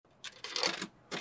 【環境音シリーズ】自動販売機
今回は、どこにでもある自動販売機で収録しました。
交通量の少ない時間帯に収録しました。
TASCAM(タスカム) DR-07Xのステレオオーディオレコーダー使用しています。